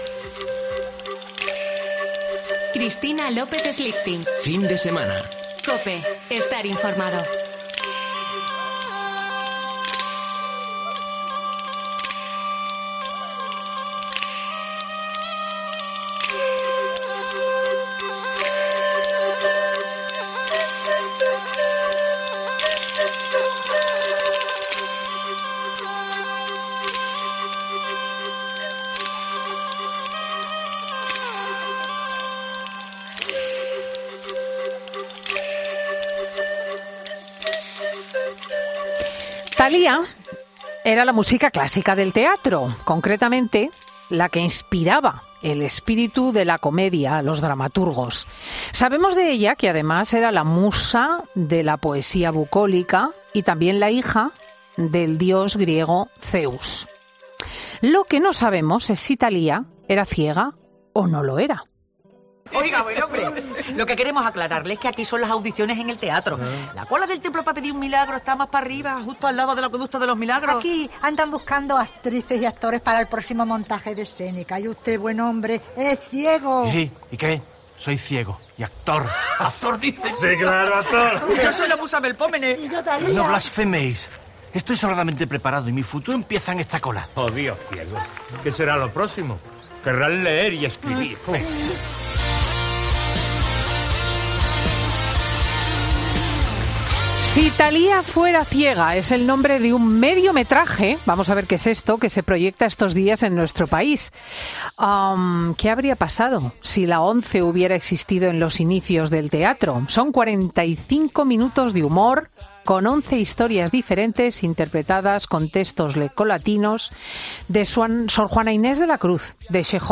han pasado por los micrófonos de Fin de Semana de COPE para explicar cómo fue la experiencia de un rodaje tan particular.